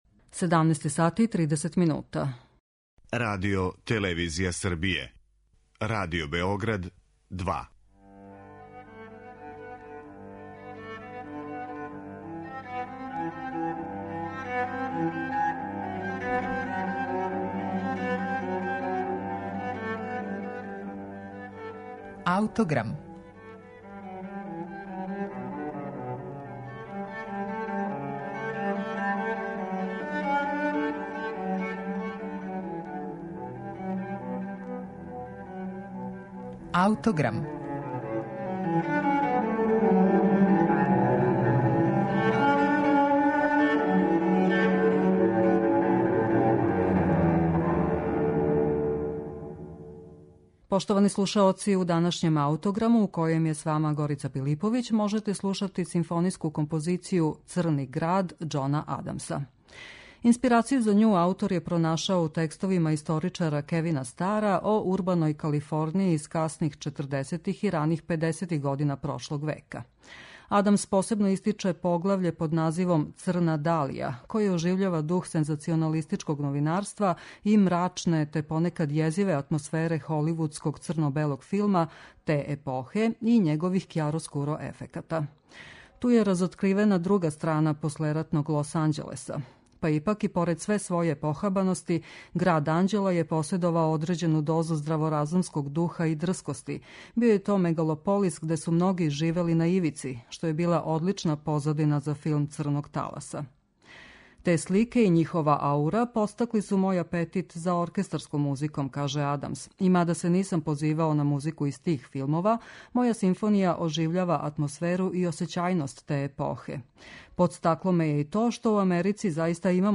Музика прожета џезом